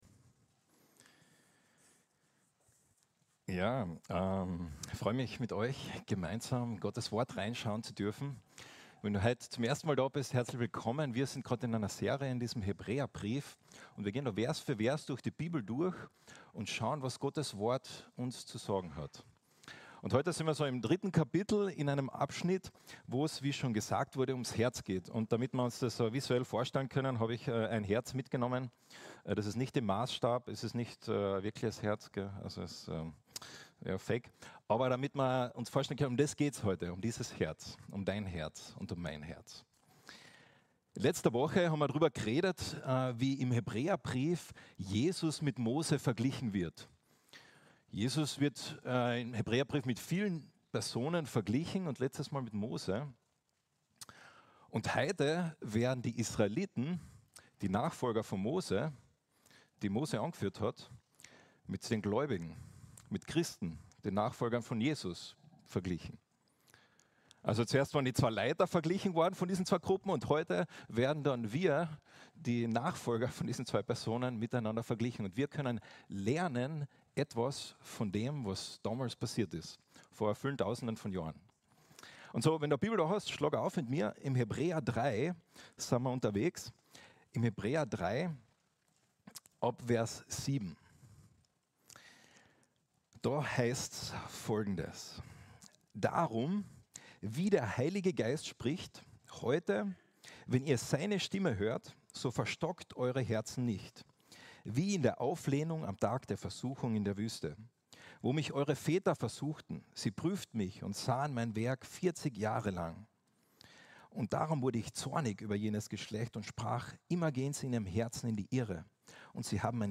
Sermons – Archive – FEG Klagenfurt